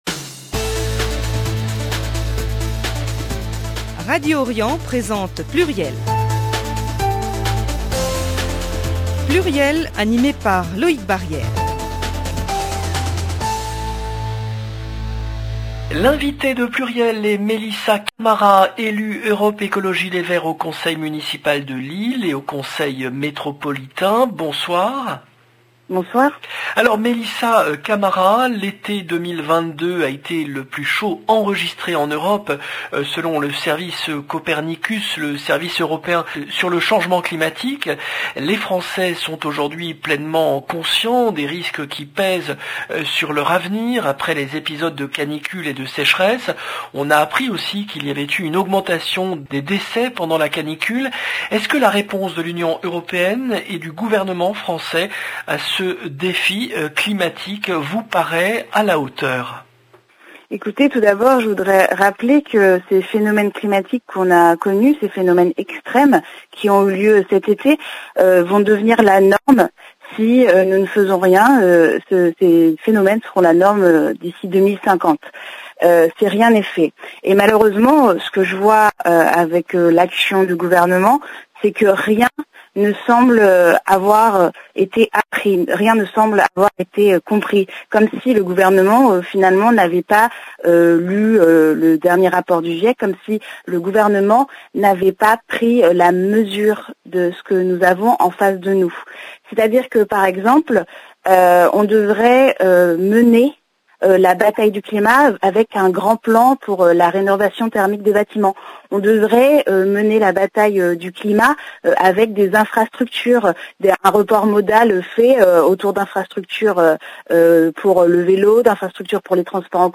Mélissa Camara, élue EELV au conseil municipal de Lille